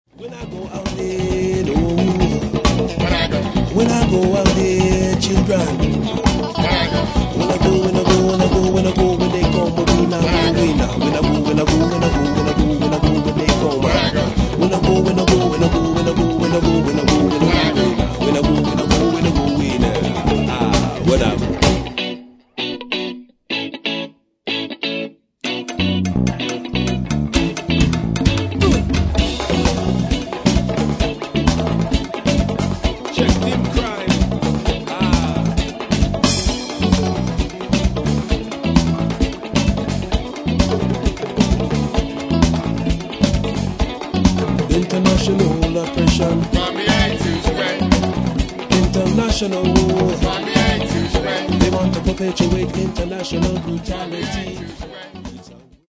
Hip Hop, Reggae, Funk, Latin Music, World Music
which brims with a hot Cuban / samba undercurrent